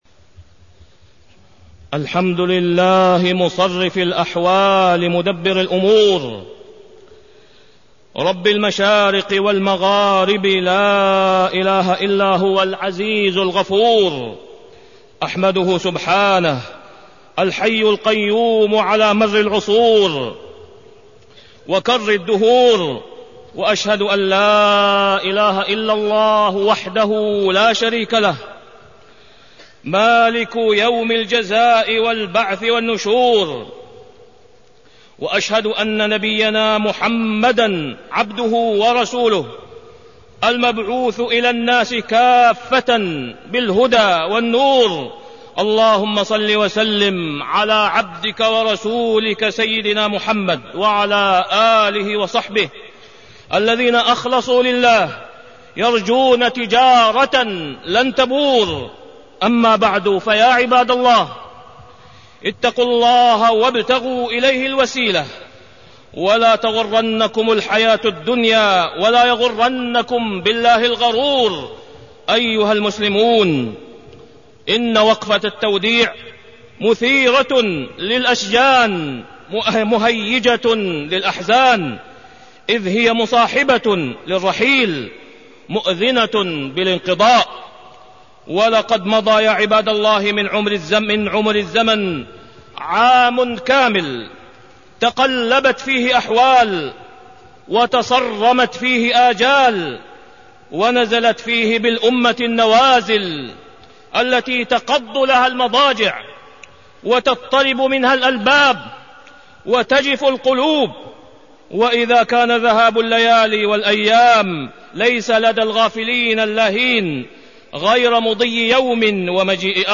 تاريخ النشر ٢٤ ذو الحجة ١٤٢٢ هـ المكان: المسجد الحرام الشيخ: فضيلة الشيخ د. أسامة بن عبدالله خياط فضيلة الشيخ د. أسامة بن عبدالله خياط محاسبة النفس